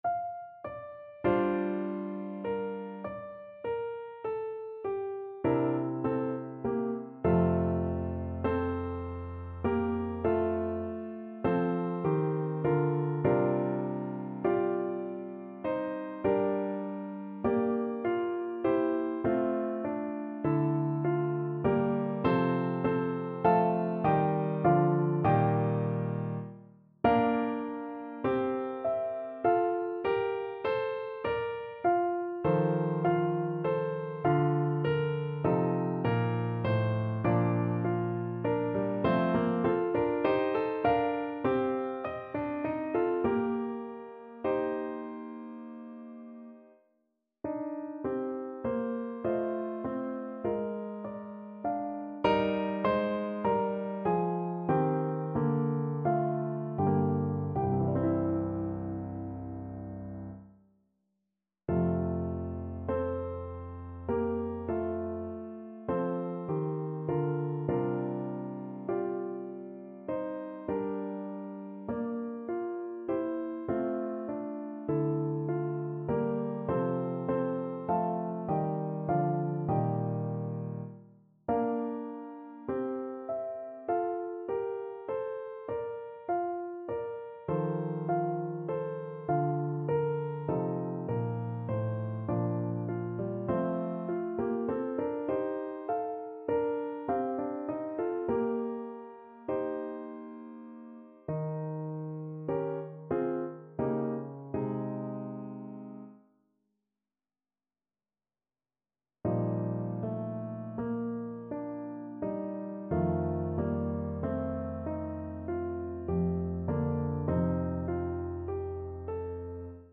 5/4 (View more 5/4 Music)
Classical (View more Classical Clarinet Music)